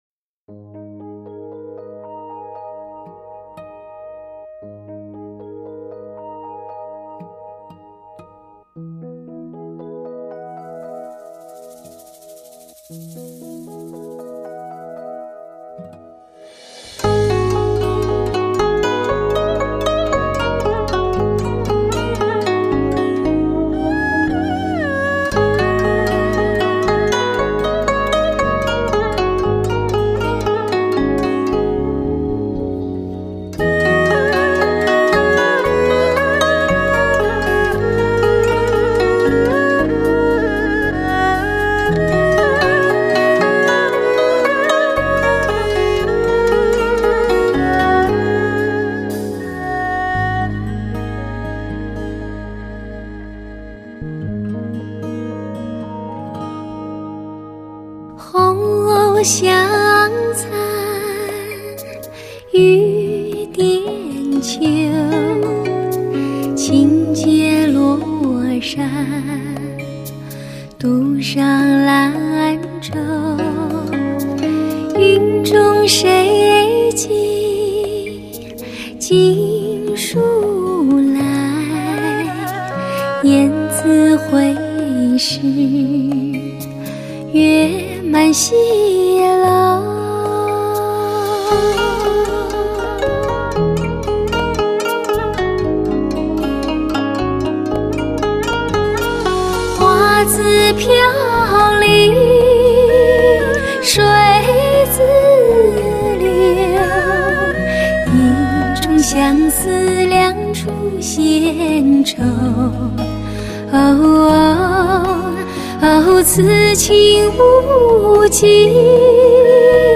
婉转流畅 细腻动人
独具传统中国古文化格调，唱腔婉转流畅，表现细腻动人，